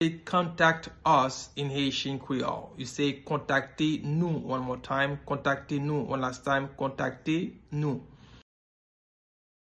Pronunciation:
Listen to and watch “Kontakte Nou” audio pronunciation in Haitian Creole by a native Haitian  in the video below:
6.How-to-say-Contact-Us-in-Haitian-Creole-–-Kontakte-Nou-pronunciation.mp3